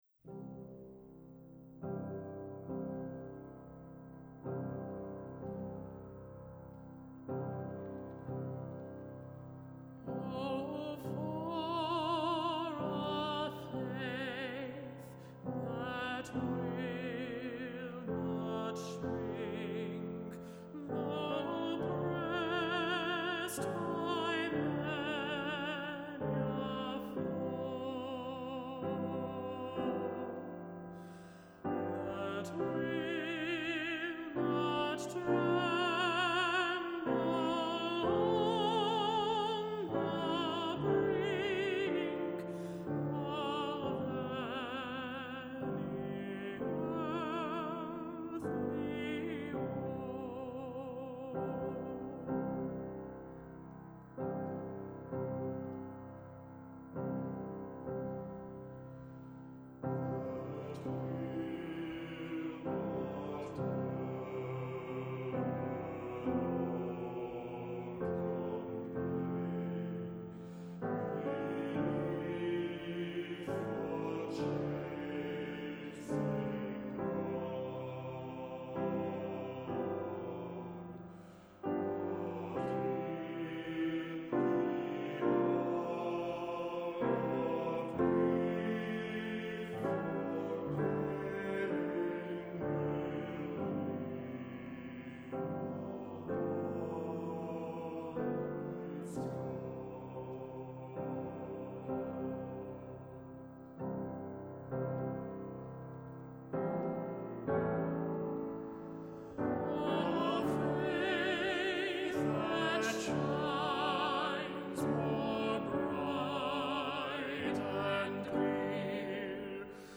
Voicing: "SATB","Alto Solo","Bass Solo"